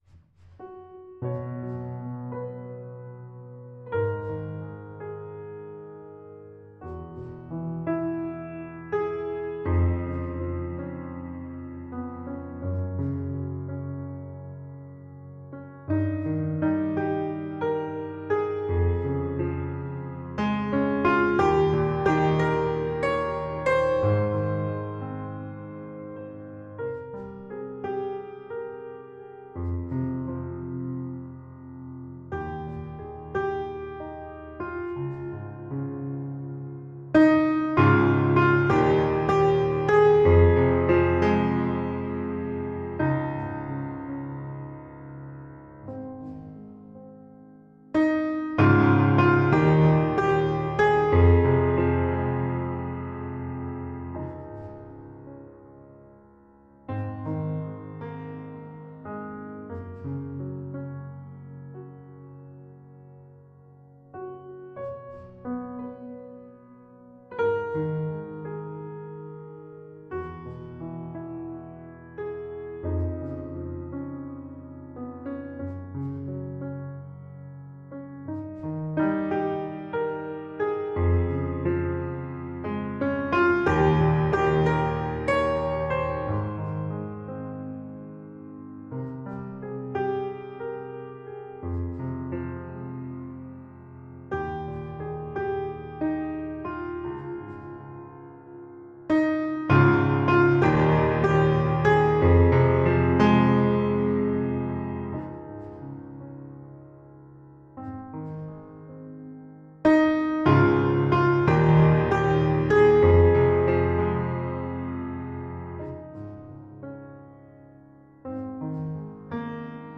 Emotional piano explorations.